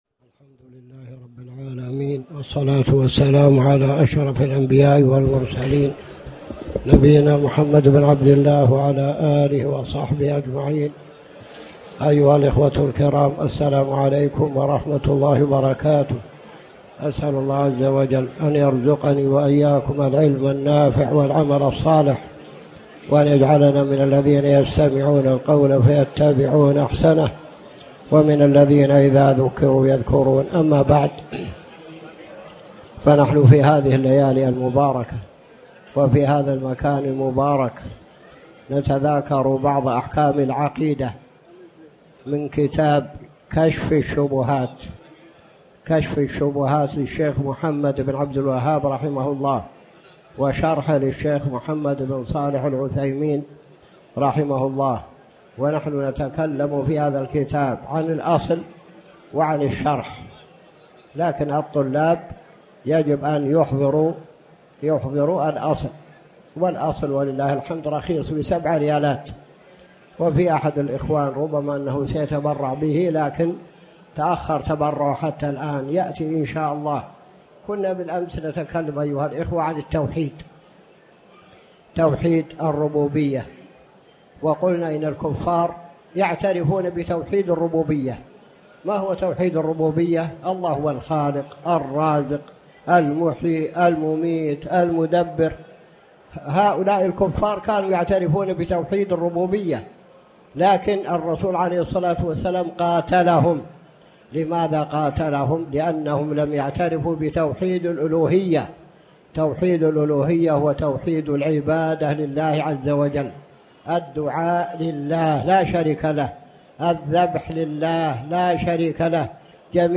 تاريخ النشر ٣ جمادى الآخرة ١٤٣٩ هـ المكان: المسجد الحرام الشيخ